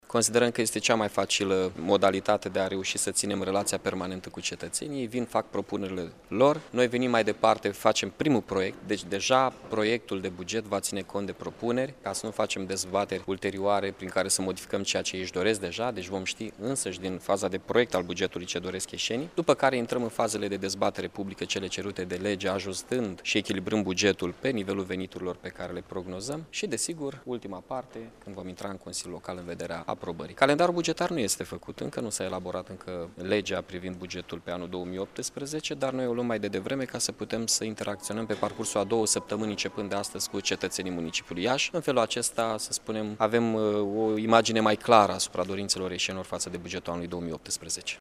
Primarul Mihai Chirica a anunţat că au fost tipărite 5000 de chestionare care vor fi distribuite în centrele de cartier.
27-nov-rdj-17-Chirica-consultare.mp3